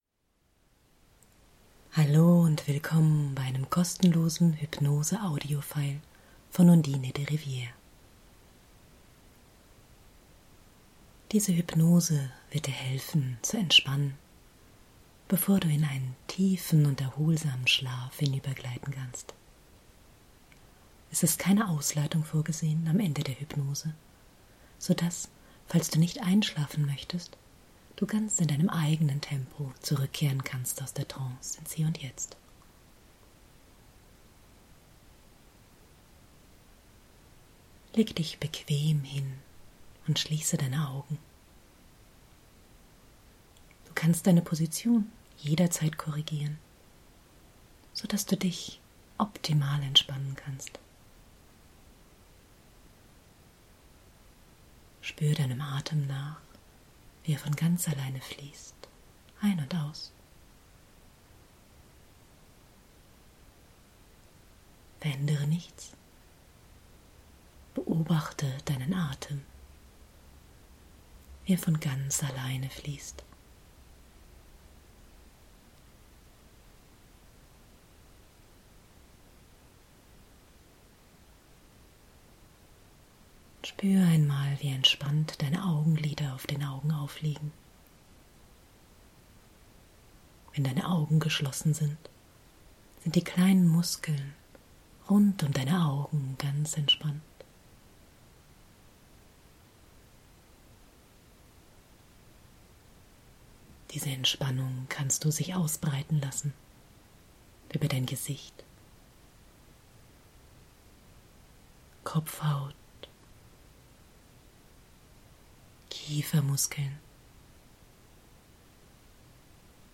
Weil ich nun schon so oft gehört habe, dass meine kleine Leerhypnose zur Entspannung vor dem Schlafen verwendet wird, habe ich euch einmal eine richtige Schlaf-Hypnose aufgenommen. Sie dient nicht nur der körperlichen Entspannung, sondern auch dem mentalen Abschalten und sorgt dafür, dass ihr wichtige, aber beim Einschlafen vielleicht störende…